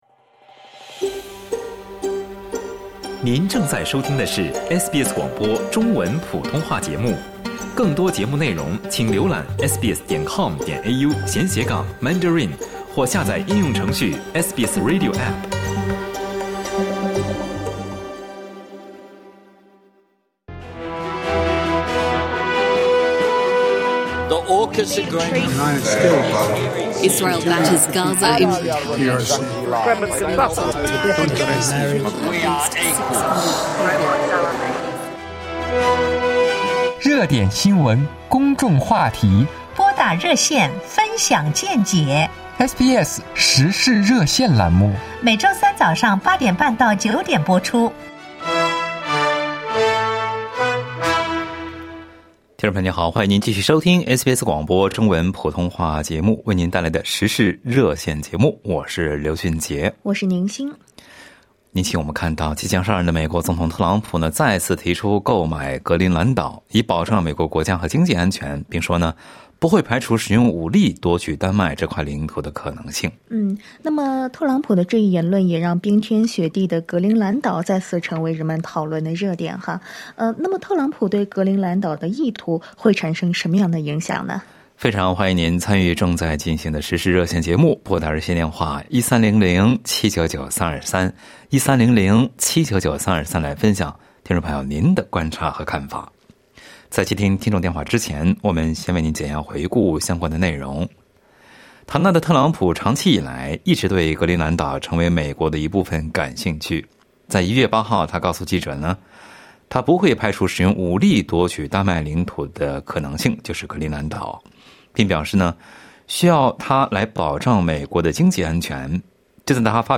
在本期《实施热线》节目中，听友们就特朗普购买格陵兰岛意图的影响发表了各自的看法。